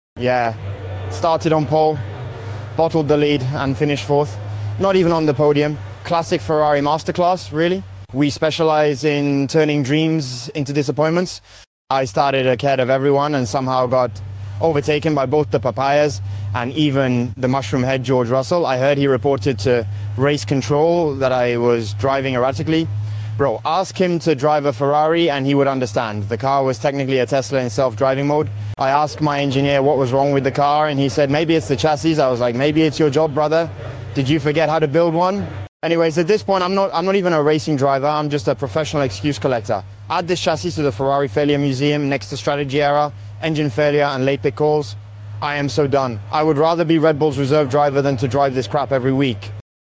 Charles Leclerc Post Race Interview and sound effects free download
Charles Leclerc Post-Race Interview and Reaction | Hungarian Grand Prix 2025